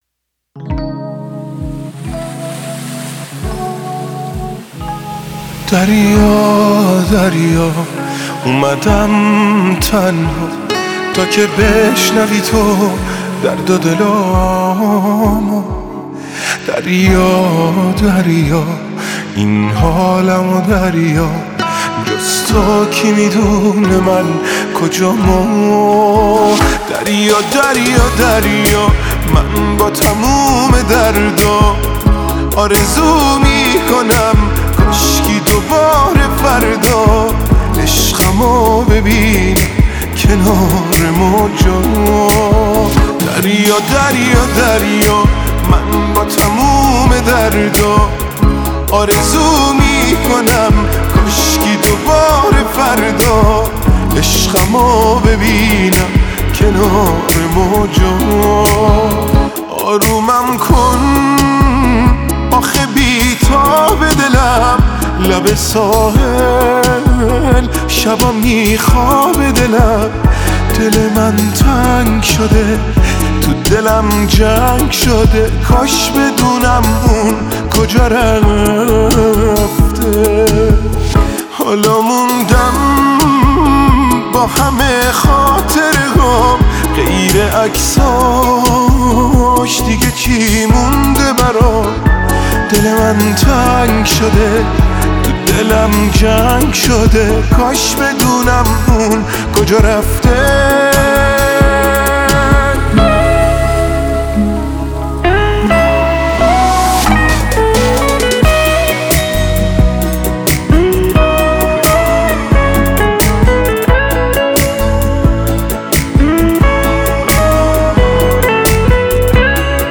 خواننده سبک پاپ